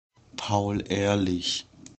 Paul Ehrlich (German: [ˈpaʊl ˈʔeːɐ̯lɪç]